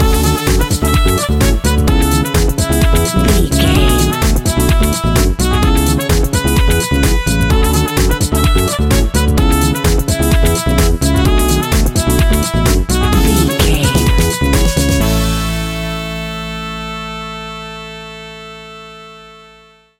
Ionian/Major
groovy
uplifting
bouncy
cheerful/happy
electric guitar
horns
bass guitar
drums
upbeat
instrumentals
wah clavinet
fender rhodes
synth bass
saxophones